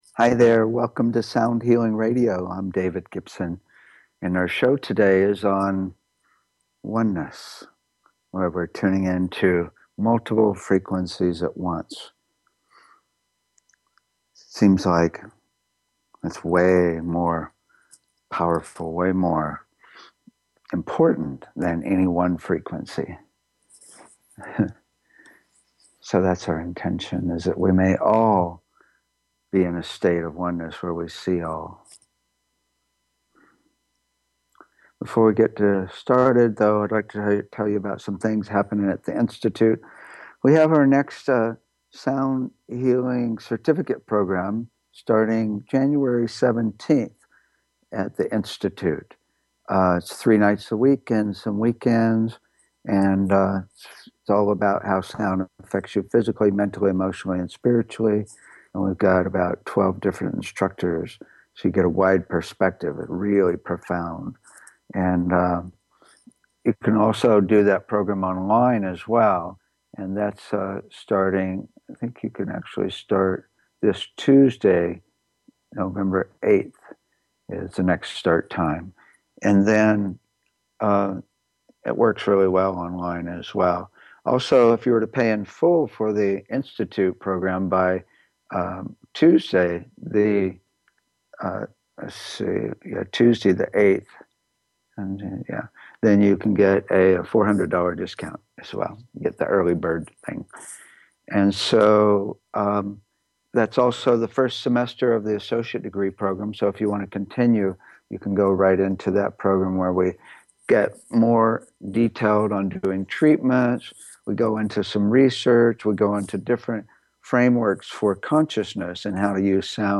Talk Show Episode, Audio Podcast, Sound Healing and The Sounds of Oneness on , show guests , about Sounds of Oneness, categorized as Health & Lifestyle,Sound Healing,Kids & Family,Music,Philosophy,Psychology,Self Help,Spiritual
The show is a sound combination of discussion and experience including the following topics: Toning, Chanting and Overtone Singing - Root Frequency Entrainment - Sound to Improve Learning -Disabilities - Using Sound to Connect to Spirit - Tuning Fork Treatments - Voice Analysis Technologies - Chakra Balancing - Sound to Induce Desired States of Being - Tibetan/Crystal Bowl Massage - Electronic Nerve Stimulation - Water Sound Infusion Systems - Sound Visualization systems - Infratonics Holographic Sound - Scalar wave EESystem Drumming and Rhythm - HydroAcoustic Therapy - Neurophone Bio-Tuning - Sound Surgery - Cymatics Frequency based therapeutic devices - VibroAcoustic Sound Chairs and Tables /soundhealing#archives /soundhealing#showposts /soundhealing#upcoming /customshow/2574 /customshow/mrss/2574 /soundhealing#feedback BBS Station 1 Bi-Weekly Show -e- 7:00 pm CT 7:55 pm CT Saturday Education Energy Healing Sound Healing Love & Relationships Emotional Health and Freedom Mental Health Science Self Help Spiritual 0 Following Login to follow this talk show Sound Healing